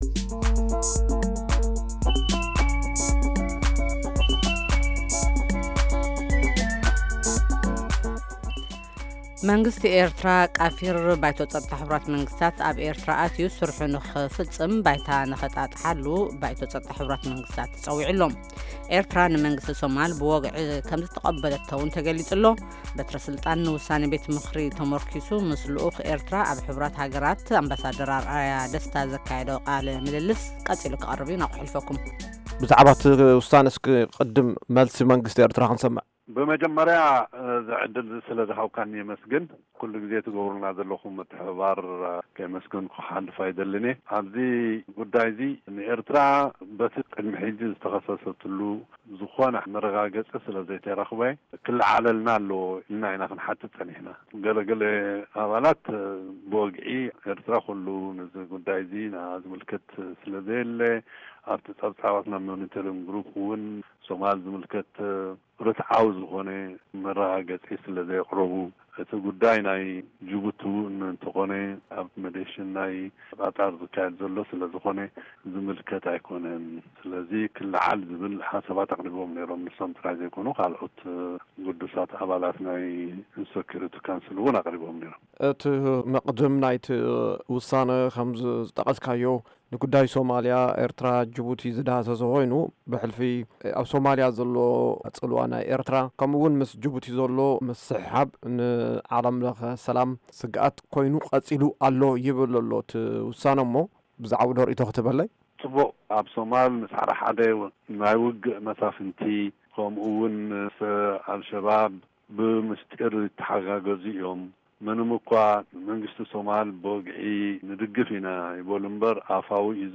ኣብ ሕቡራት ሃገራት ቀዋሚ ልኡኽ ኤርትራ ኣምባሳደር ኣርኣያ ደስታ፣ንቪኦኤ ኣብ ዝሃቦ ቃለ-መጠይቅ‘ዩ ነዚ ኣረጋጊፁ።